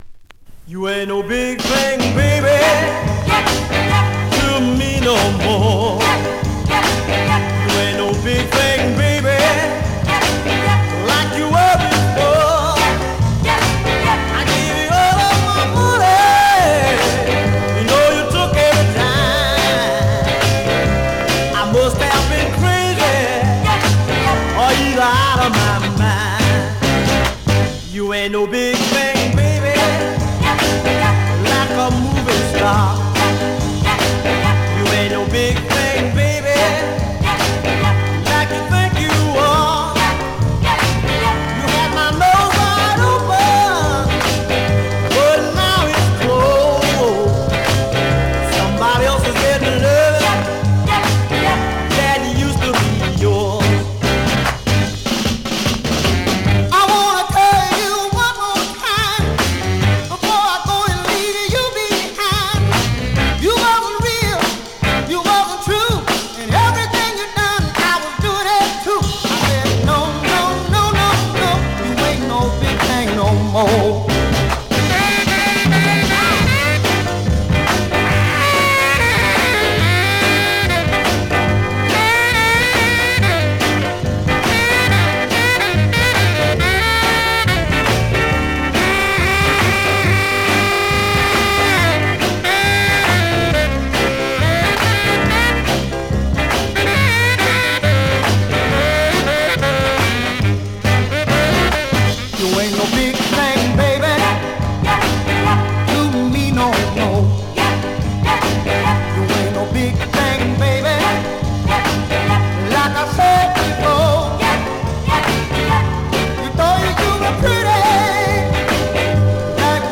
Great mid-tempo Rnb / Northern dancer .